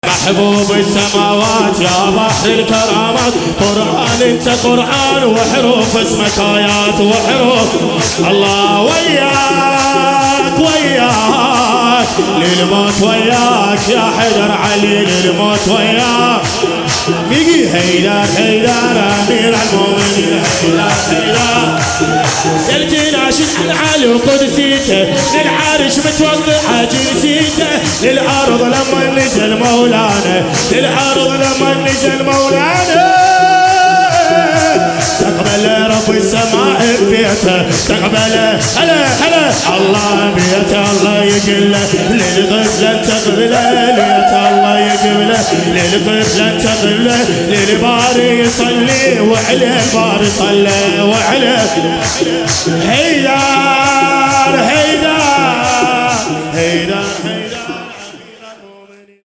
ملا محمد معتمدی ولادت حضرت معصومه (س) مجمع هیئات محسنیه کربلاییها 5 تیر 99